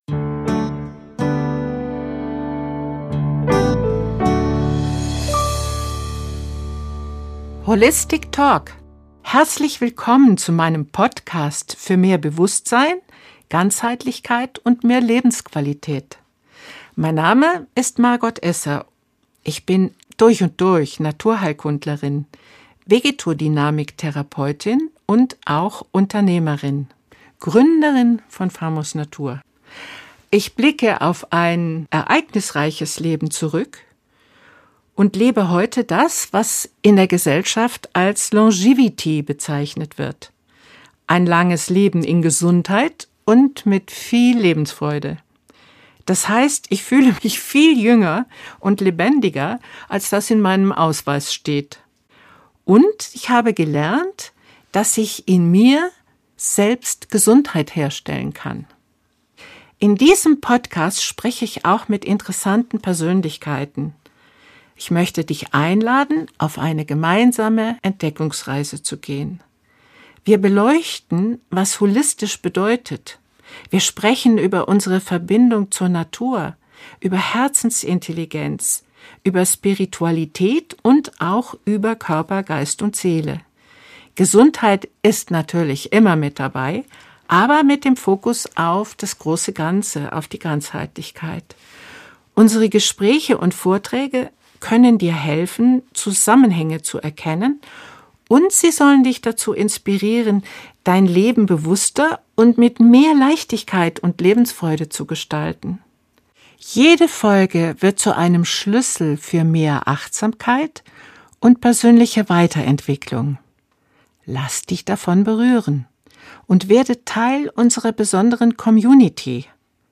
Zum ersten Mal begegnen sich die beiden nicht über Bildschirm, sondern vis-à-vis: ein intensives Gespräch über Liebe als Frequenz, die Weisheit des Körpers, die heilige Kraft der Pflanzen, und darüber, warum echte Gesundheit immer innen beginnt.